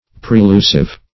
Search Result for " prelusive" : The Collaborative International Dictionary of English v.0.48: Prelusive \Pre*lu"sive\, a. [See Prelude .] Of the nature of a prelude; introductory; indicating that something of a like kind is to follow.